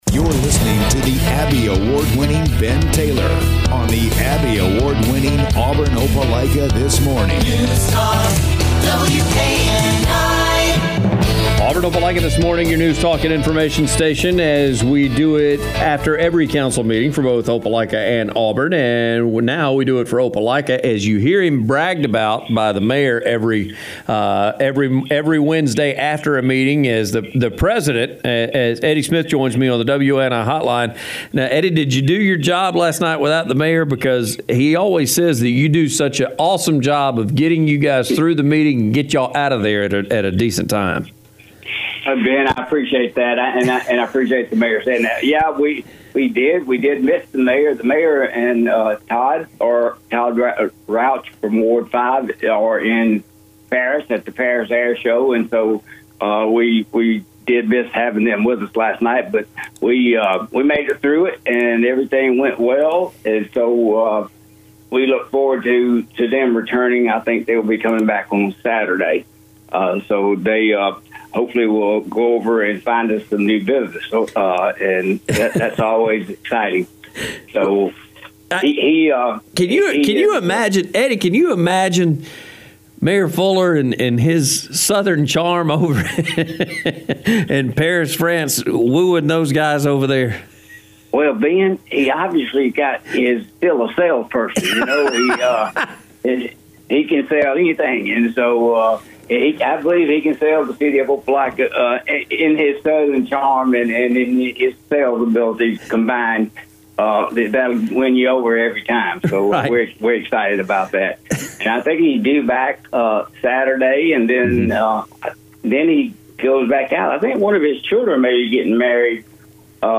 Opelika City Council President, Eddie Smith joined AOTM to review the Opelika City Council meeting...